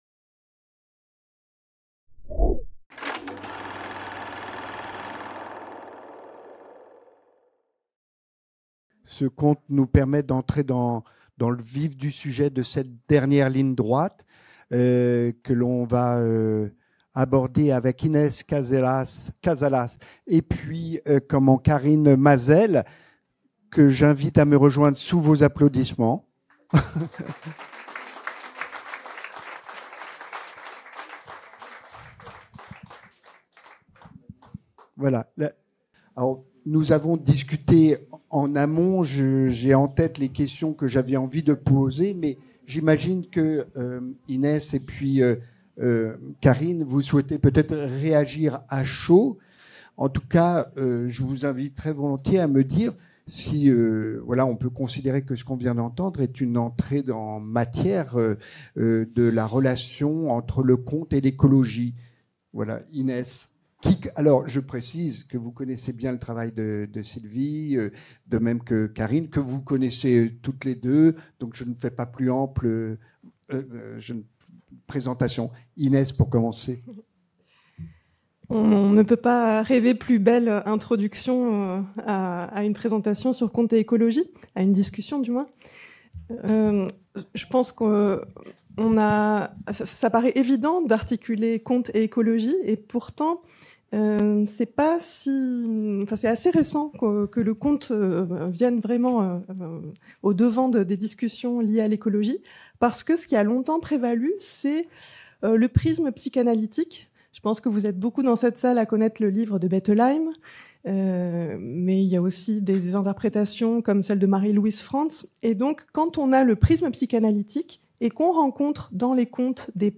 Troisième partie de la rencontre à la FMSH, pour explorer le pouvoir des contes, à l'occasion de la nuit blanche parisienne, qui s'est tenue le 7 juin 2025